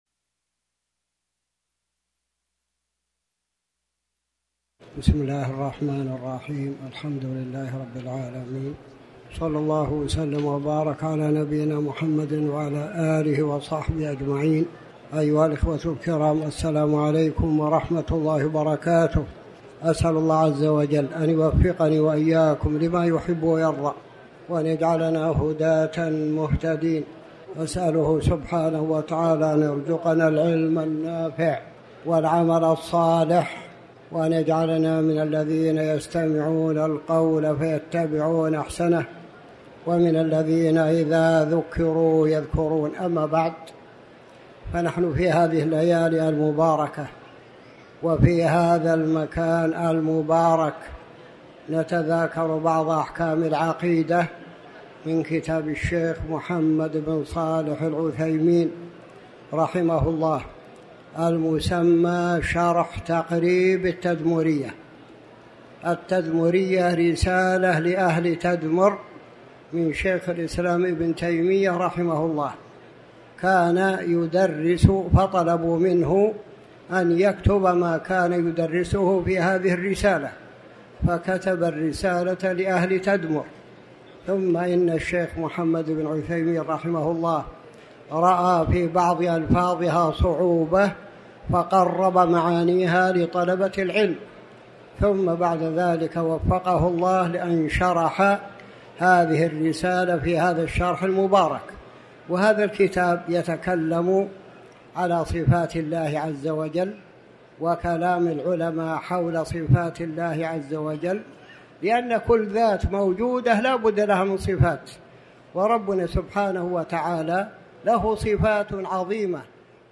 تاريخ النشر ٢٦ ربيع الثاني ١٤٤٠ هـ المكان: المسجد الحرام الشيخ